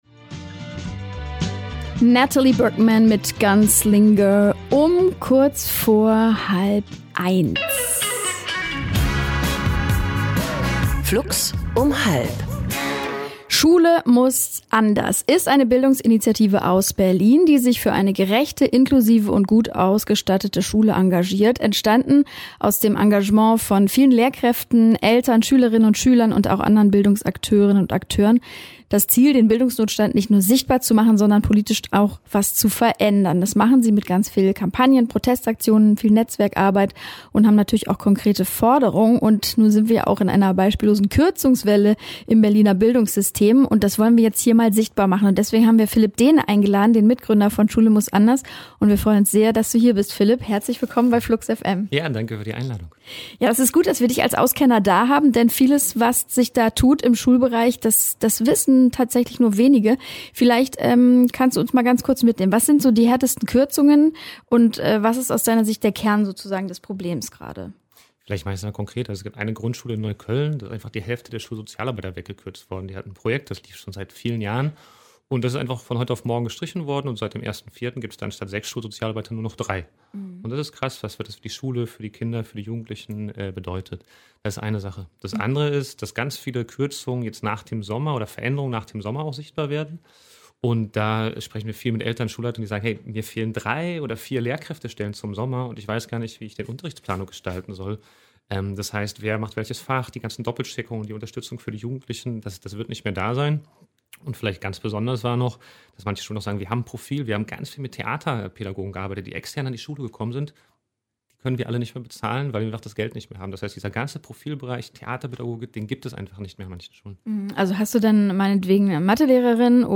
0703-IV-Mitschnitt-Schule-muss-anders.mp3